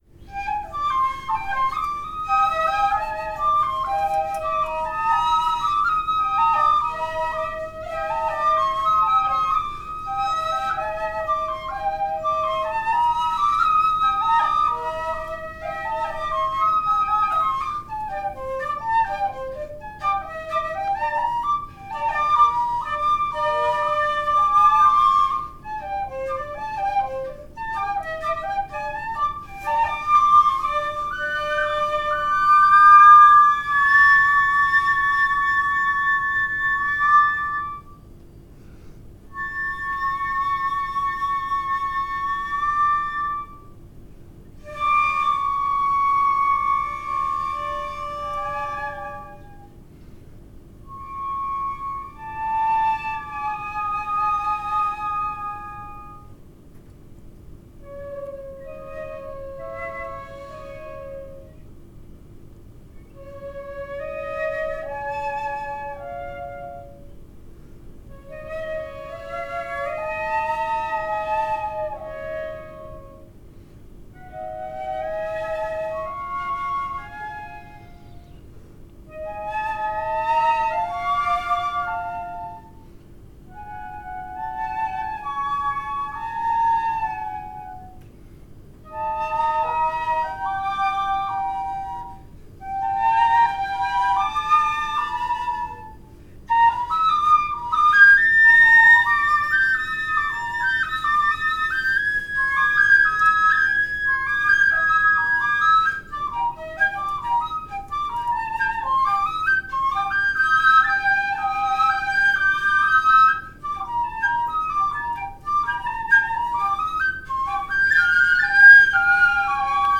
Shinobue (Japanese flute) Duet, 2m30s
The Japanese shinobue is a small transverse flute made from a single piece of bamboo.
This recording is on two size ‘six’ shinobue.
In particular, I was interested in moving between several different transpositions of the miyakobushi scale, which had the consequence of making the piece somewhat difficult for the performers.
Two shinobue, of any size, as long as they are of the same size.